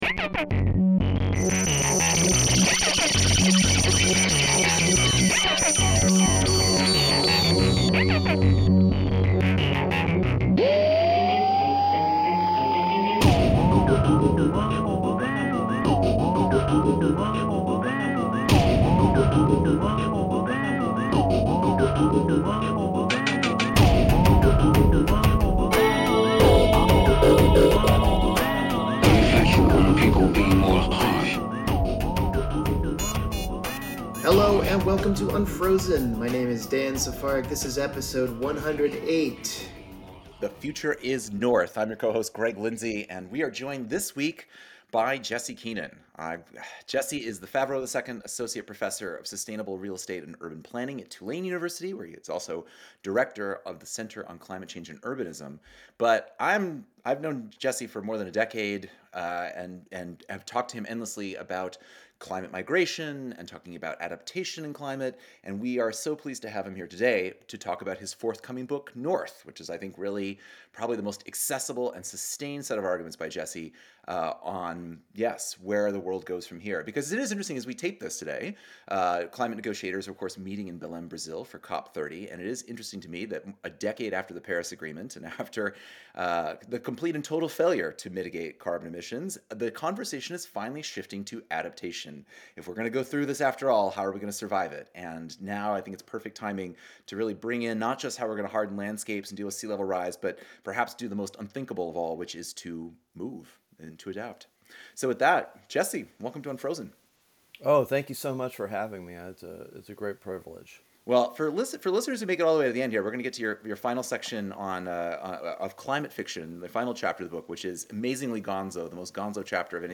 Simultaneous conclusions: There are no climate havens, but adapt we will. Join us for the fascinating Unfrozen interview.